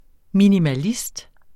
Udtale [ minimaˈlisd ]